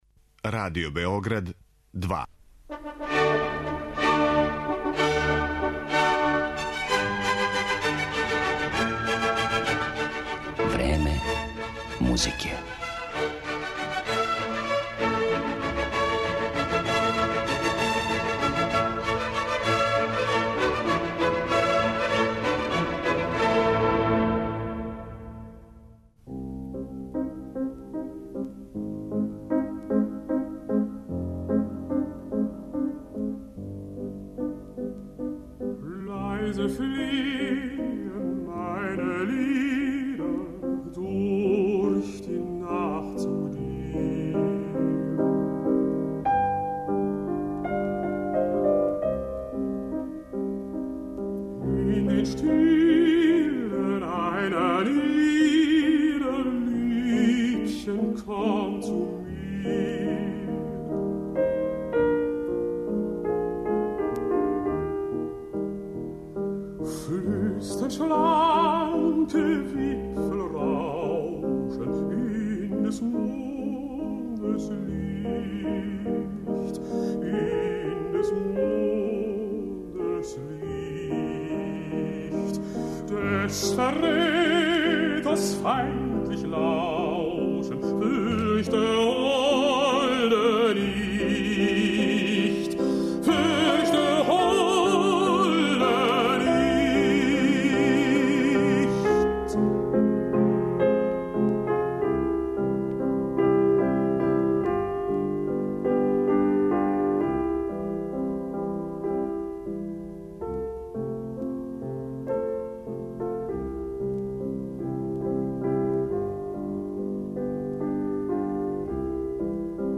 разговор
двојица колега пијаниста